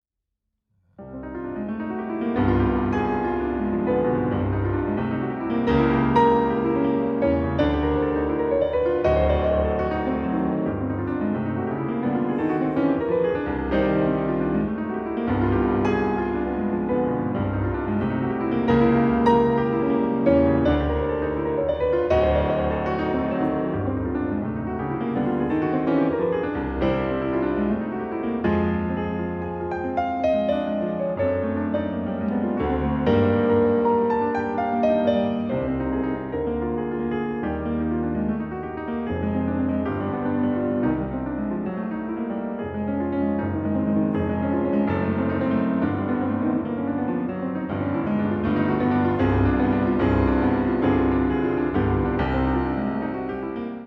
piano
Vif, passionnément